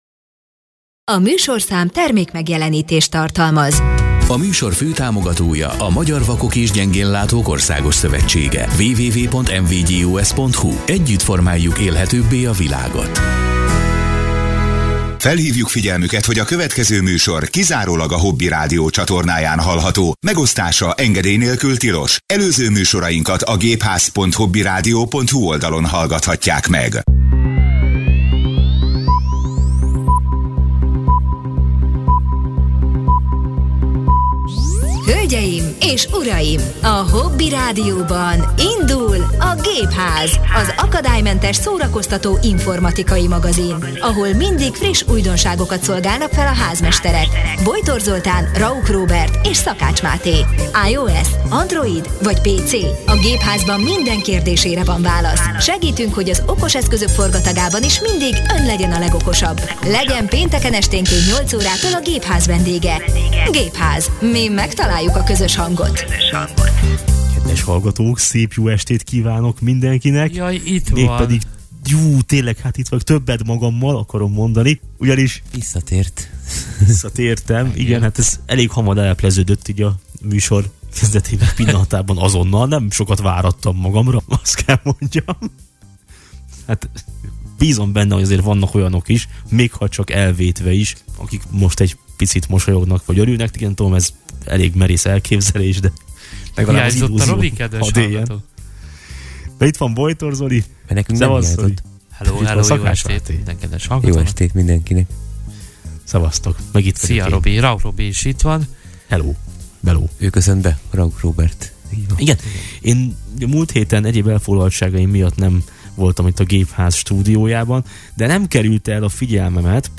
Előtte pedig egy jót beszélgettünk mindenféle érdekes, ám mérsékelten fontos informatikai aktualitásról.